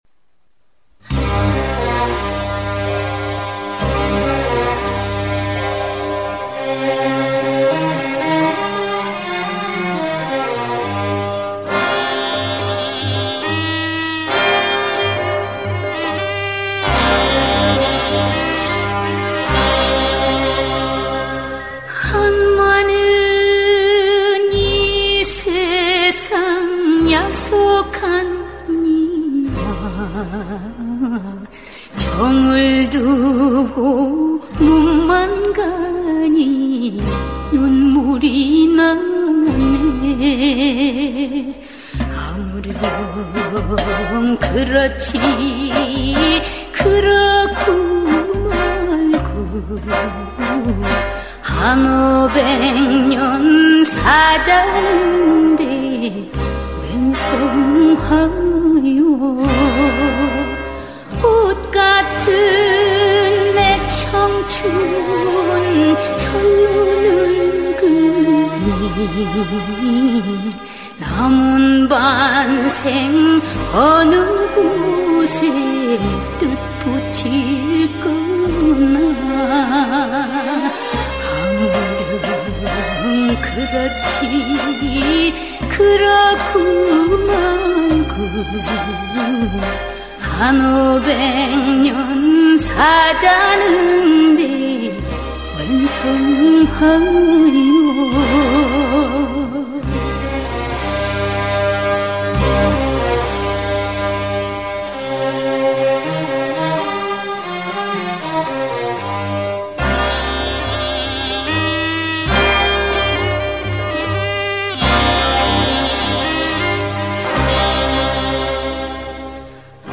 -전래민요 / OOO 편곡-